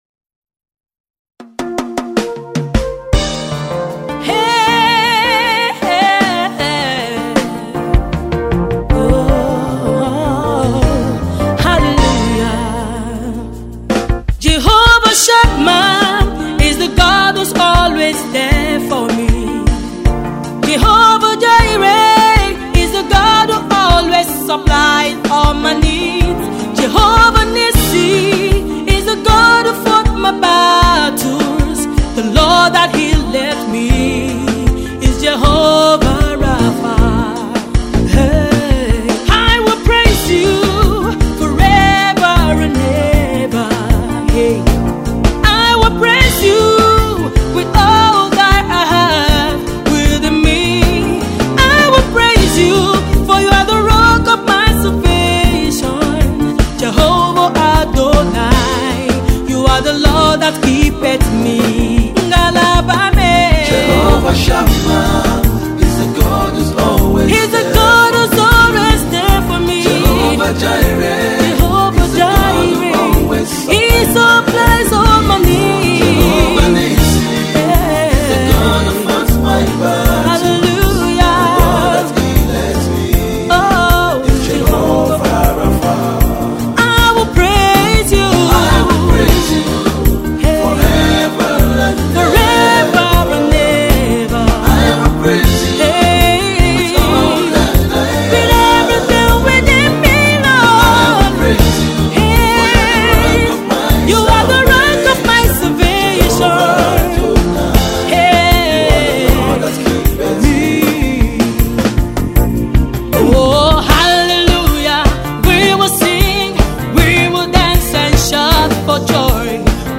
uplifting tune
features one of Nigeria’s leading gospel singers